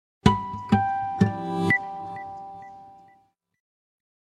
sms 2